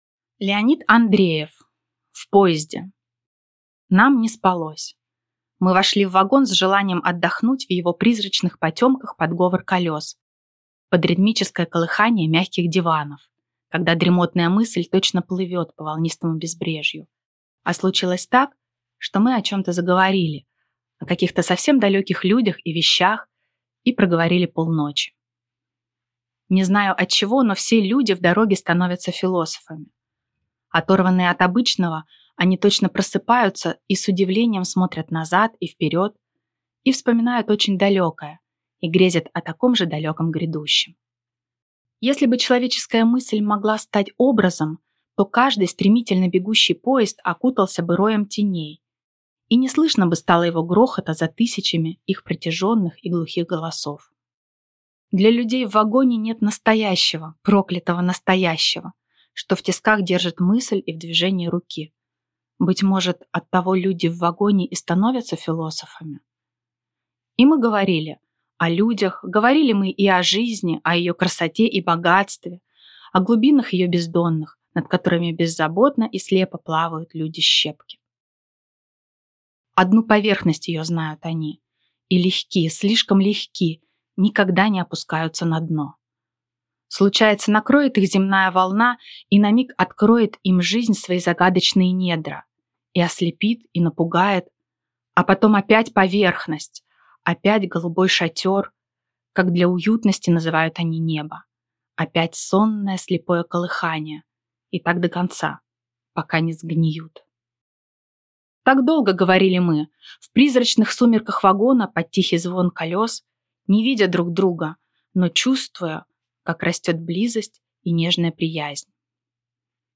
Аудиокнига В поезде | Библиотека аудиокниг